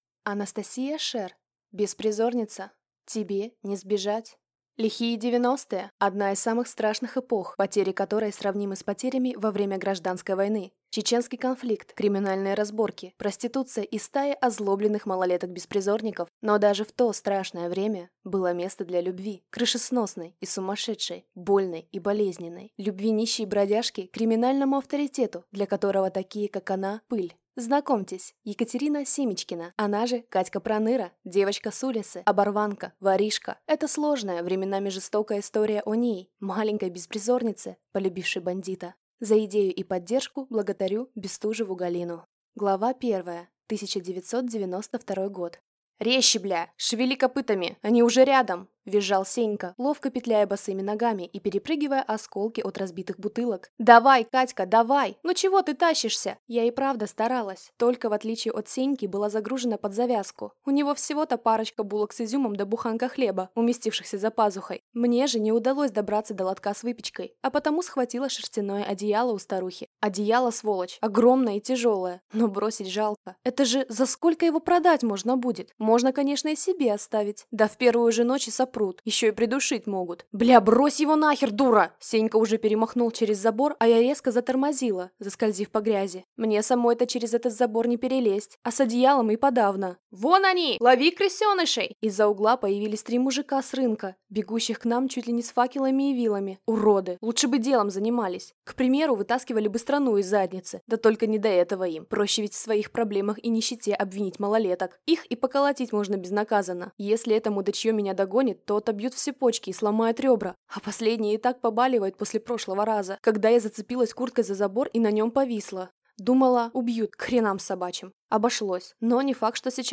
Аудиокнига Беспризорница | Библиотека аудиокниг
Aудиокнига Беспризорница